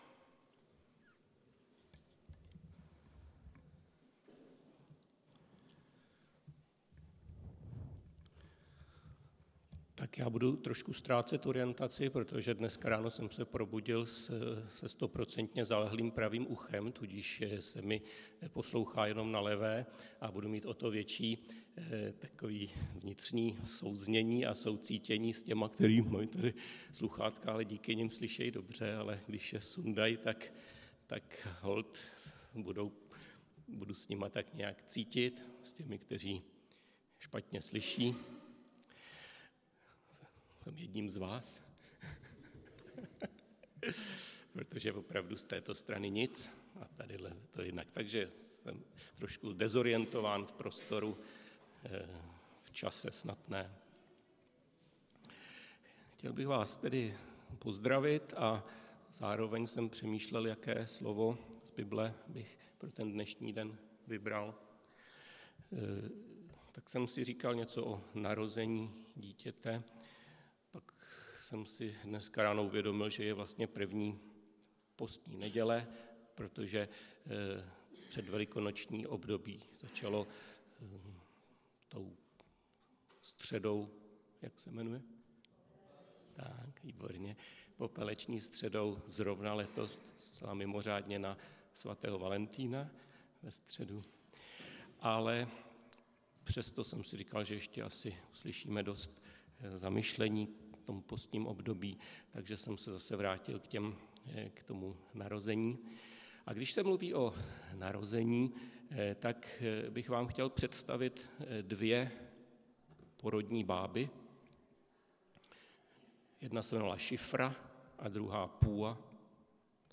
Kázání
Místo: Římská 43, Praha 2